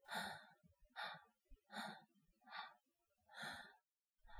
YX呼吸2.wav 0:00.00 0:04.41 YX呼吸2.wav WAV · 380 KB · 單聲道 (1ch) 下载文件 本站所有音效均采用 CC0 授权 ，可免费用于商业与个人项目，无需署名。
人声采集素材